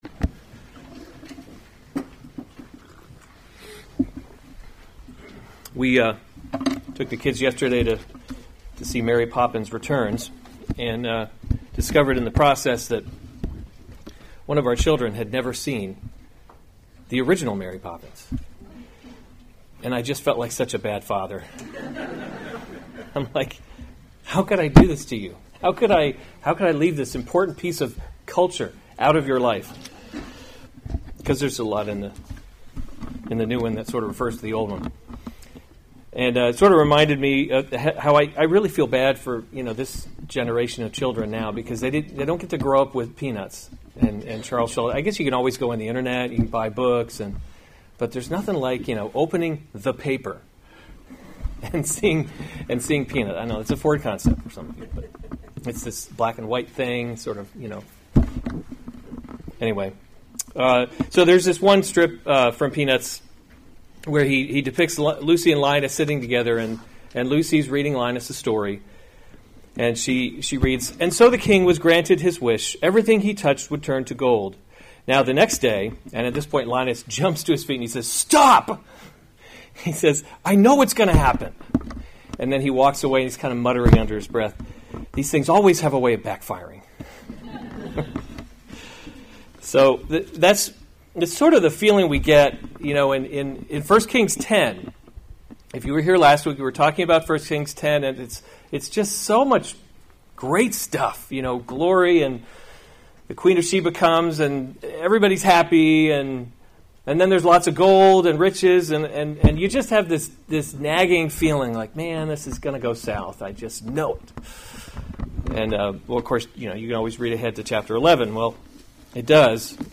February 9, 2019 1 Kings – Leadership in a Broken World series Weekly Sunday Service Save/Download this sermon 1 Kings 11:1-13 Other sermons from 1 Kings Solomon Turns from the […]